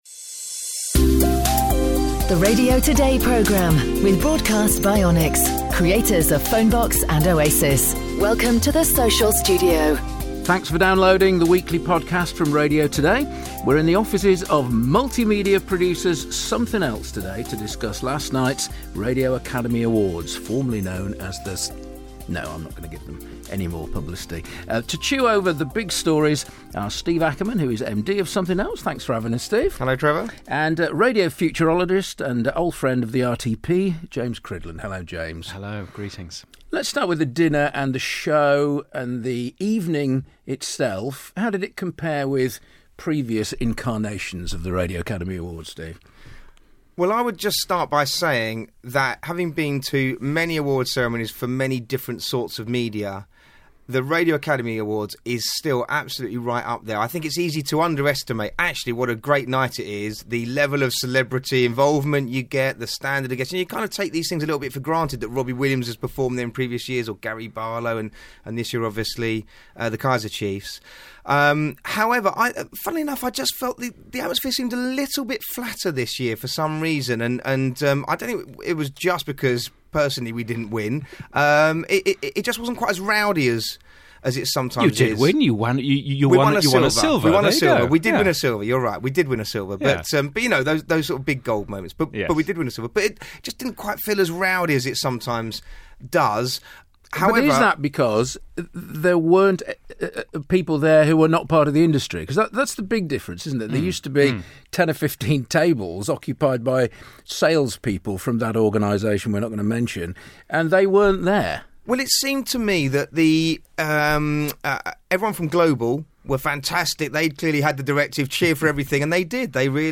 Plus news (and actuality from the awards)